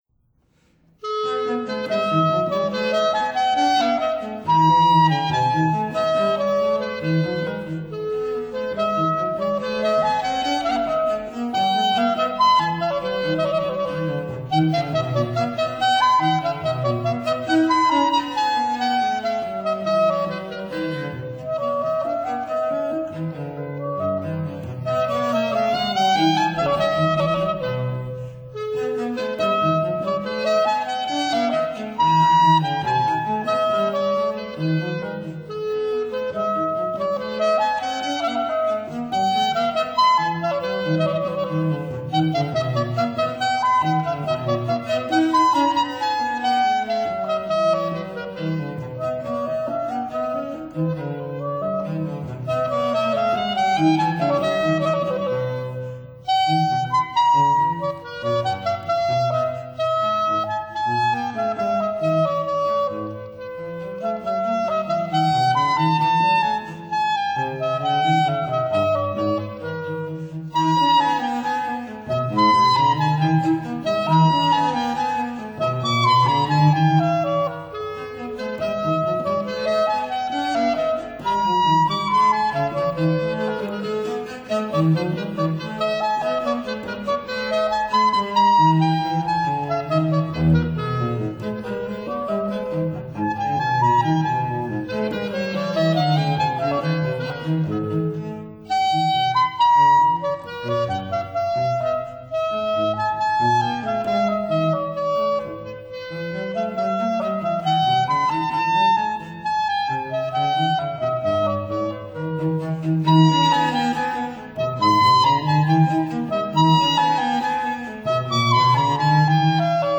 12 Sonatas for Clarinet & Cello
Clarinet in C
Cello